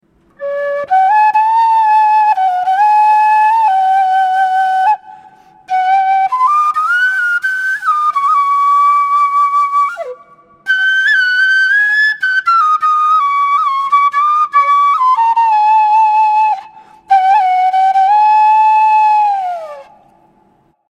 Кенилла (Quenilla, Chacon, C) Перу
Кенилла (Quenilla, Chacon, C) Перу Тональность: C
Материал: бамбук
Кенилла (Quenilla) - продольная флейта открытого типа, разновидность флейты кена.